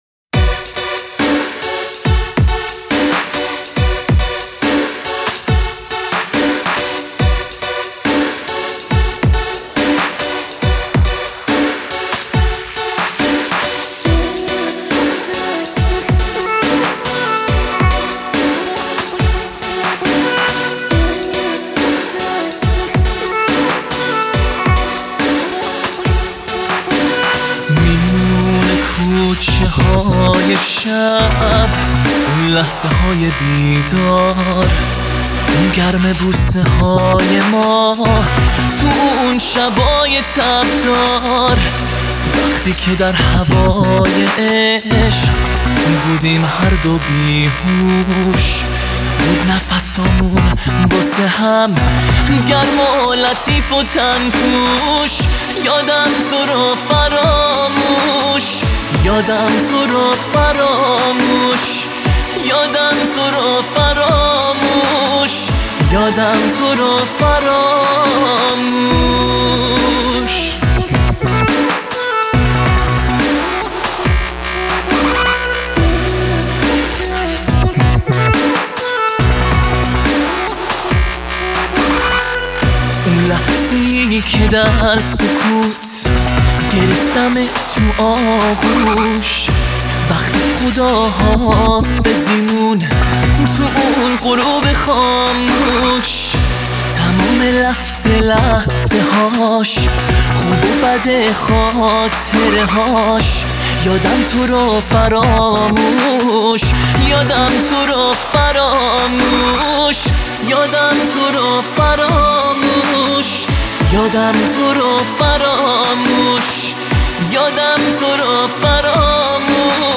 Dubstep Remix